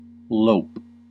Ääntäminen
Ääntäminen US : IPA : /loʊp/ UK : IPA : /ləʊp/ Haettu sana löytyi näillä lähdekielillä: englanti Käännöksiä ei löytynyt valitulle kohdekielelle.